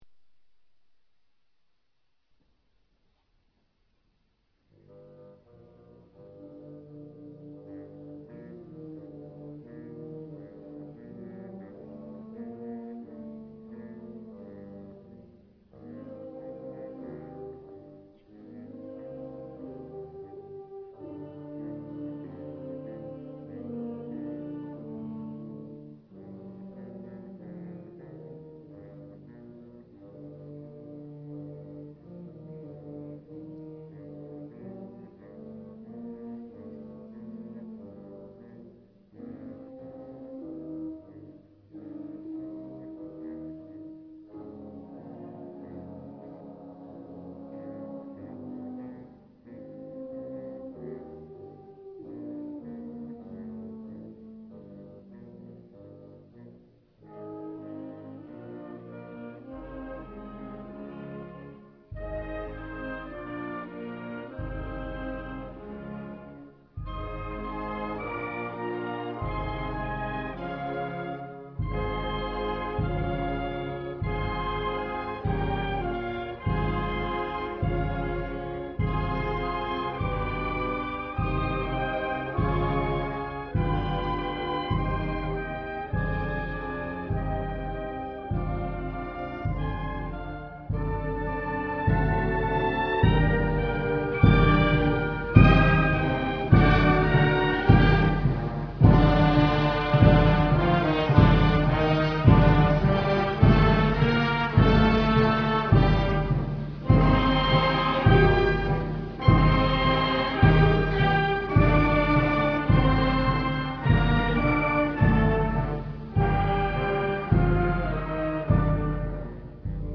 地點: 音乾乾的中山堂